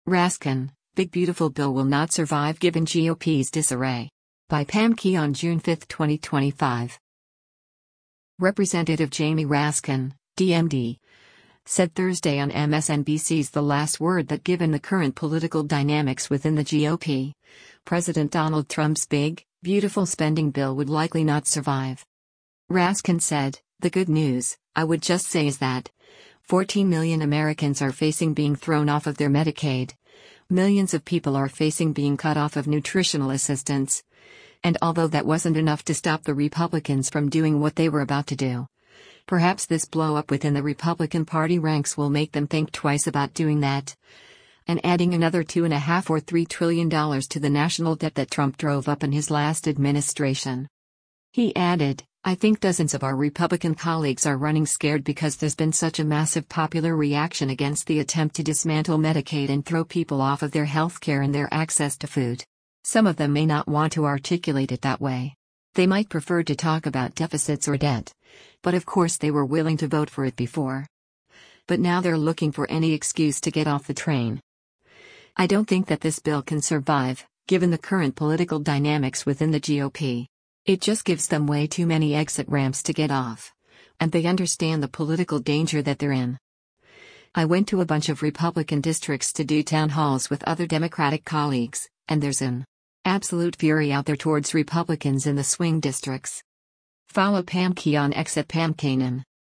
Representative Jamie Raskin (D-MD) said Thursday on MSNBC’s “The Last Word” that “given the current political dynamics within the GOP,” President Donald Trump’s “big, beautiful” spending bill would likely not survive.